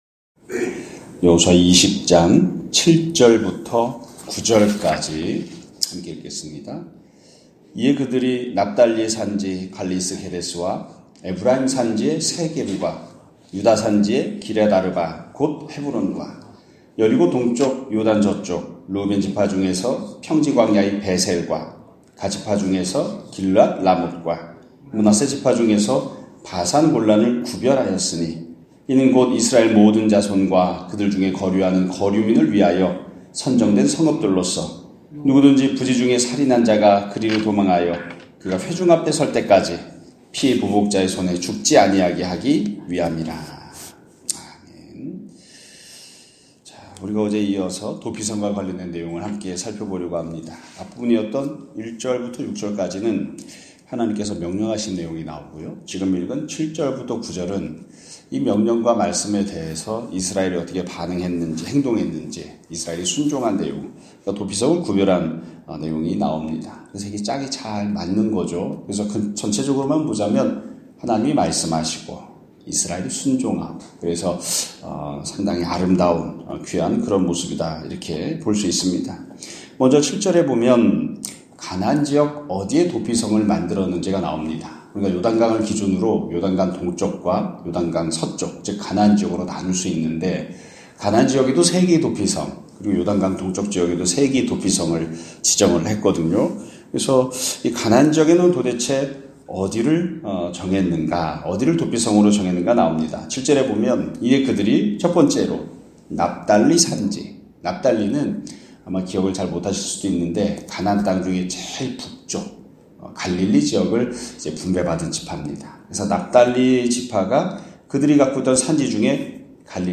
2025년 1월 16일(목요일) <아침예배> 설교입니다.